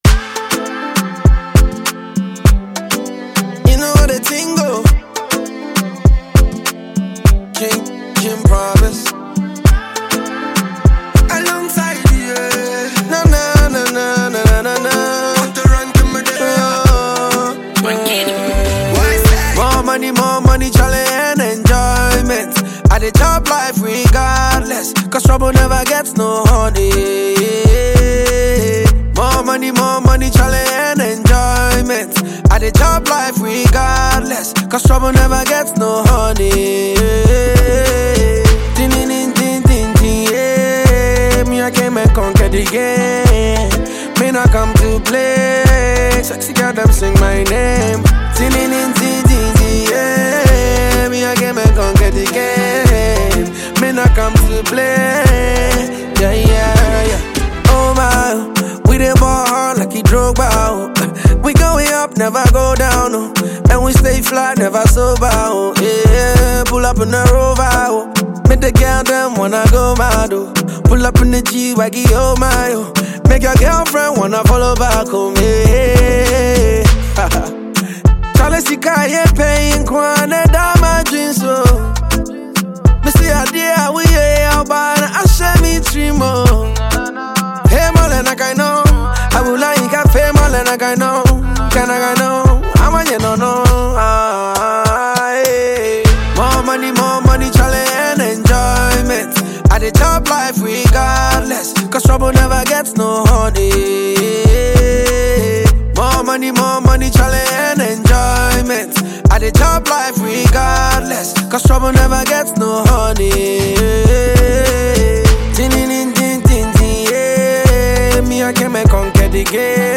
is a catchy record